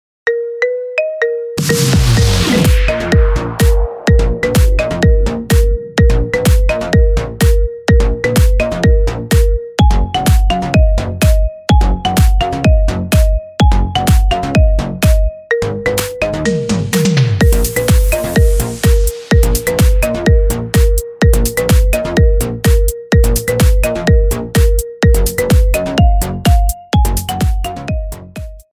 Categoria Marimba Remix